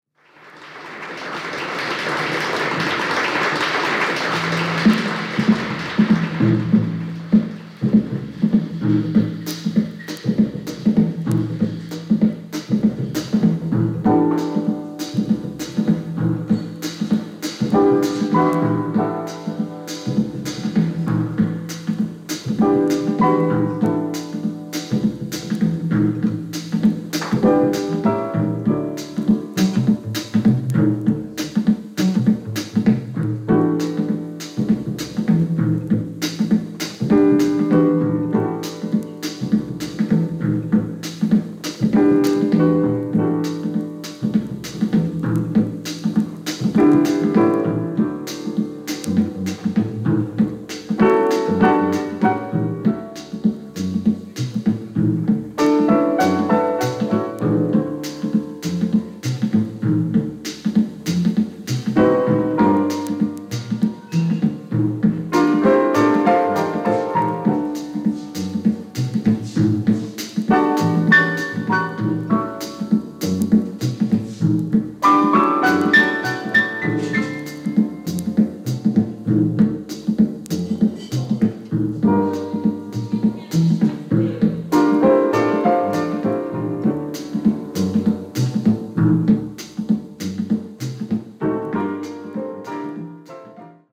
Japanese Mono盤